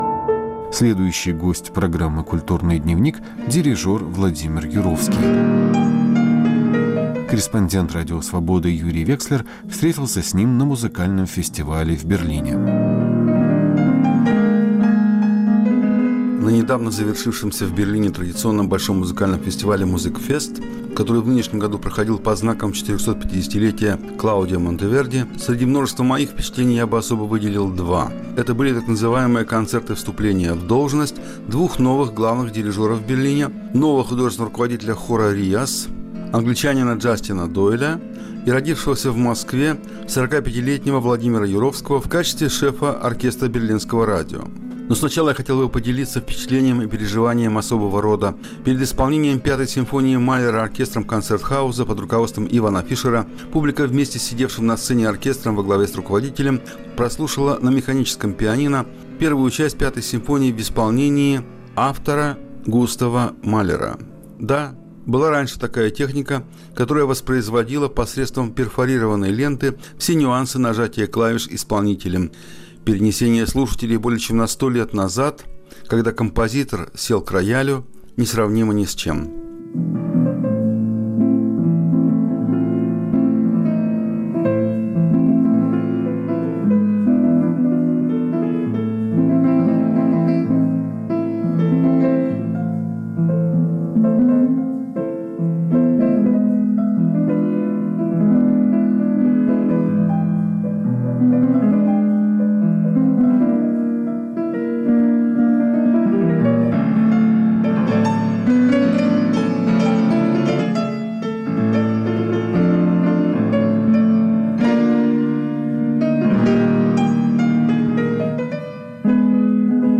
Берлинский разговор с дирижером Владимиром Юровским